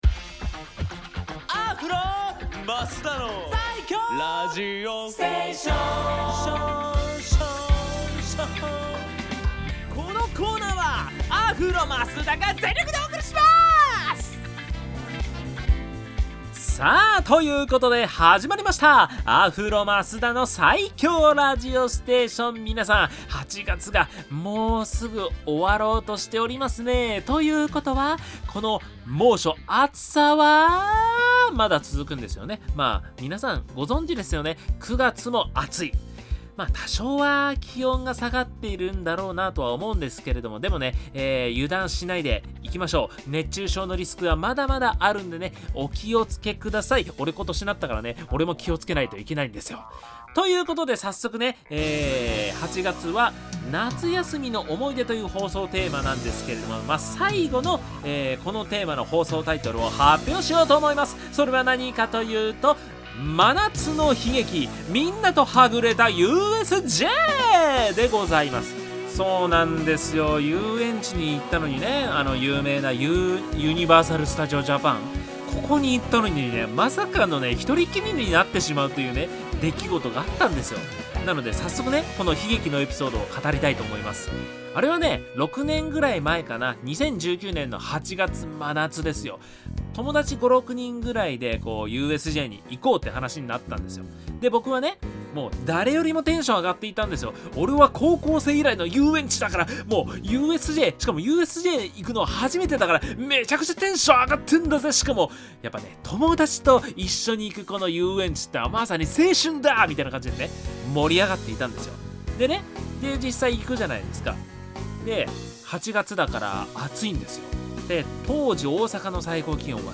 こちらが放送音源です♪